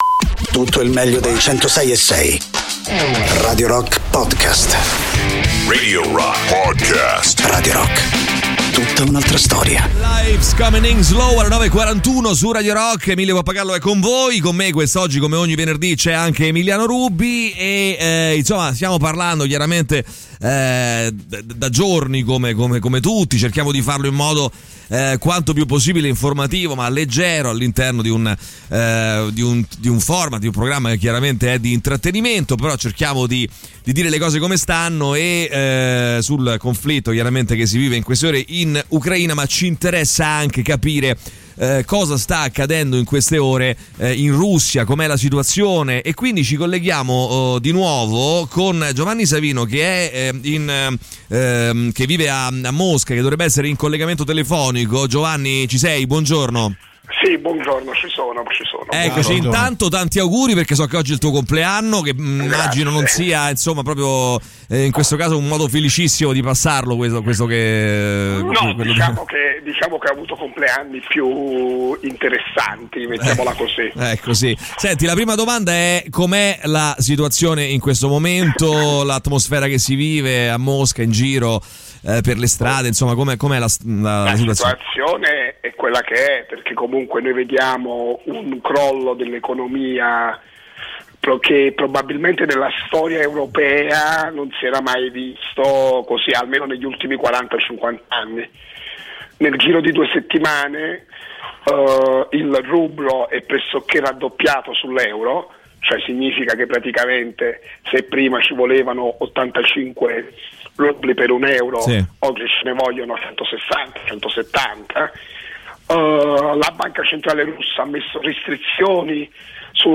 The Rock Show: Intervista